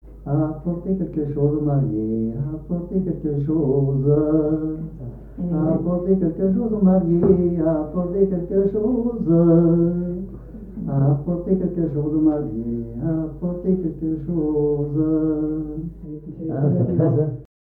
Chants brefs - De noces
danse : branle : danse de la brioche
Témoignages sur les noces et les danses
Pièce musicale inédite